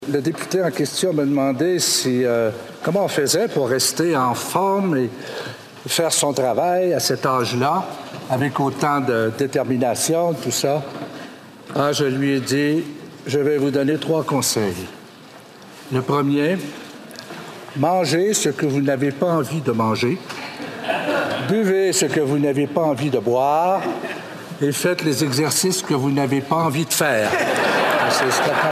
Pour la cinquième fois depuis qu’il est devenu le doyen des Communes, le député de Bécancour-Nicolet-Saurel, Louis Plamondon, a été appelé, ce lundi, à occuper momentanément le fauteuil du président de la Chambre.
Le député âgé de 78 ans et cumulant 37 ans de vie parlementaire sans interruption, s’est permis quelques blagues au sujet de sa longévité, dont cette réponse à un député.